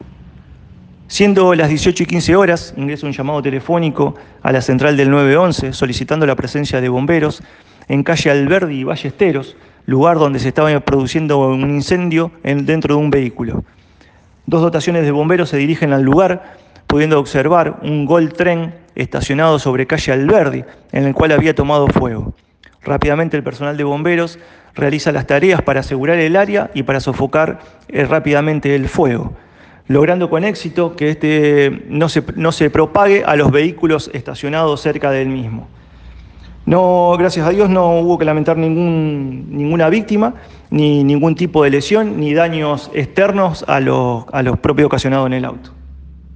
A CONTINUACIÓN EL PARTE DE PRENSA DEL CUERPO DE BOMBEROS VOLUNTARIOS DE CAÑADA DE GÓMEZ